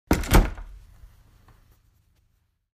door_open.mp3